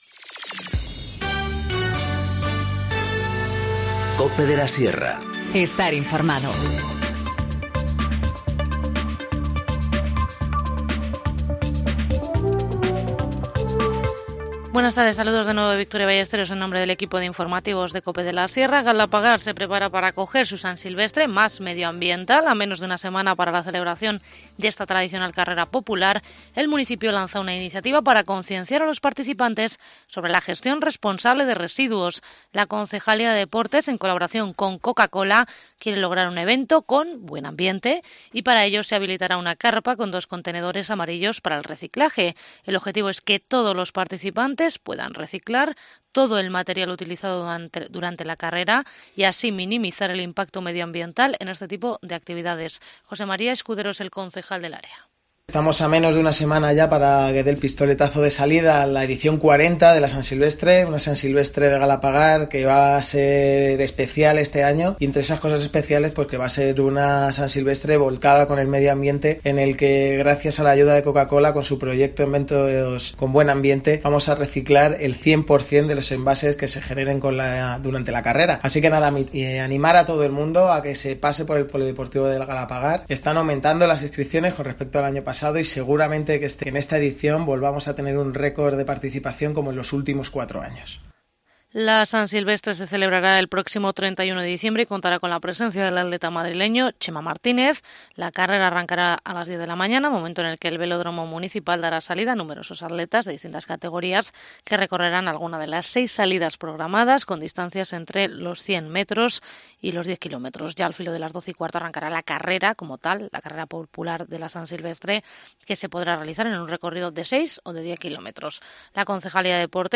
Informativo Mediodía 26 dic- 14:50h